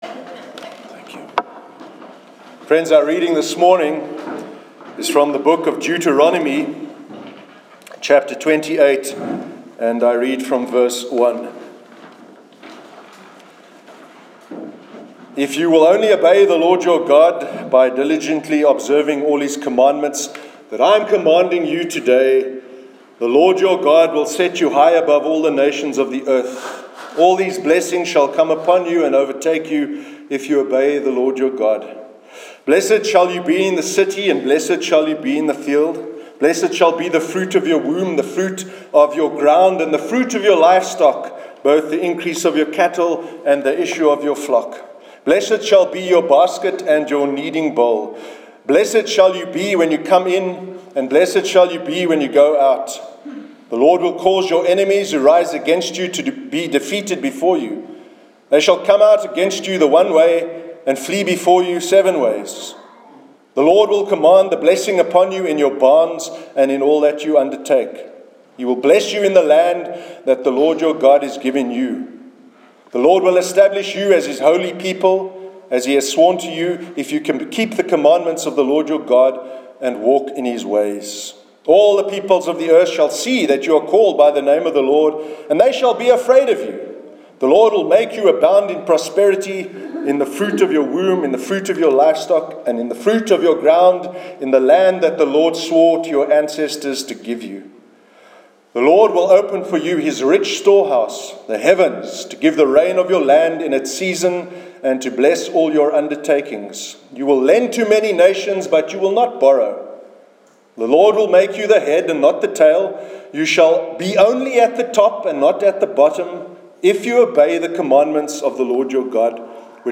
Sermon: “A brief history of hell”